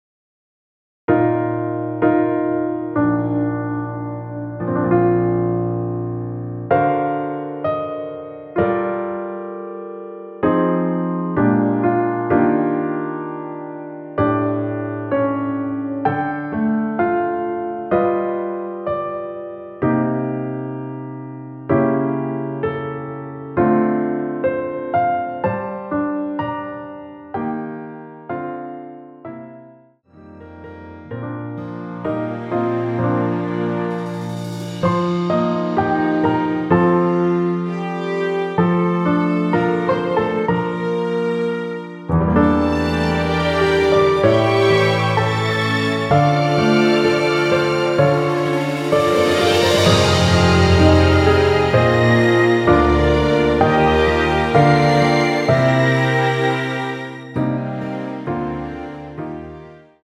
전주 없이 시작하는 곡이라 노래하기 편하게 전주 1마디 만들어 놓았습니다.(미리듣기 확인)
원키에서(+4)올린 MR입니다.
Bb
앞부분30초, 뒷부분30초씩 편집해서 올려 드리고 있습니다.